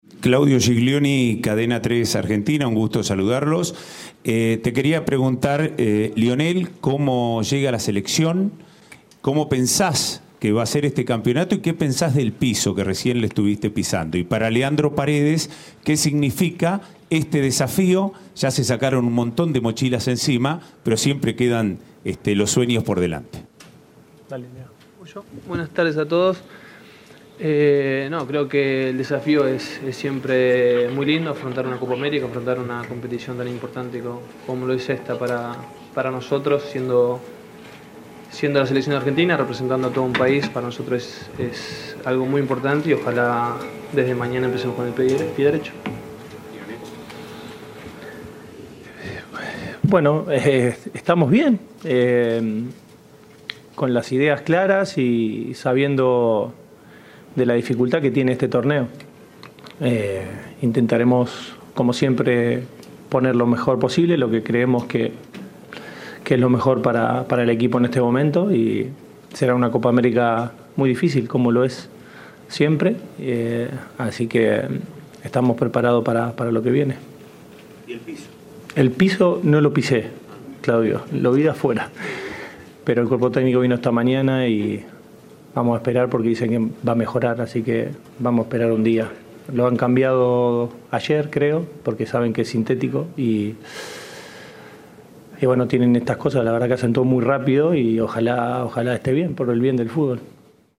A pocas horas del debut de la Selección argentina en la Copa América 2024, el director técnico Lionel Scaloni y Leandro Paredes hablaron con los medios presentes en Atlanta, entre ellos Cadena 3.